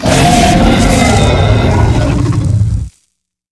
ogg / general / combat / enemy / droid / bighurt3.ogg